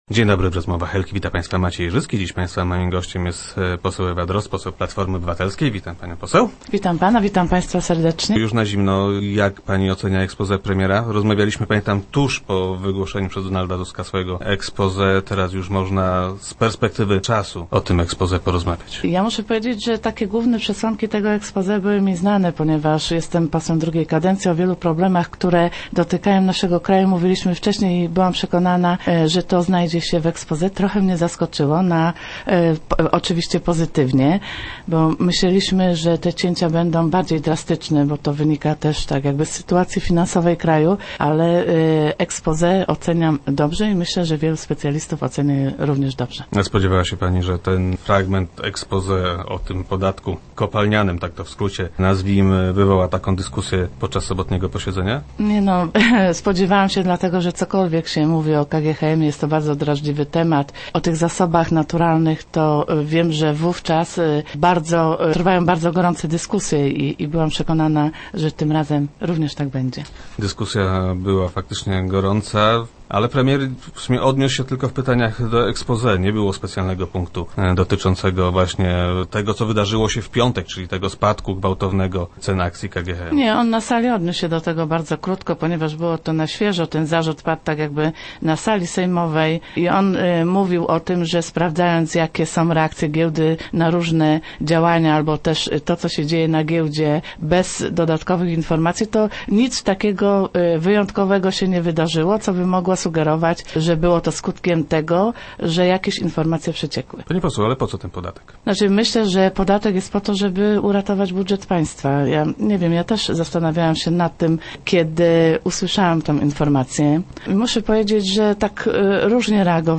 Jednym z pomysłów jest opodatkowanie wydobycia miedzi i srebra. - Myślę, że ten podatek jest potrzebny - twierdzi posłanka Ewa Drozd, która była gościem Rozmów Elki.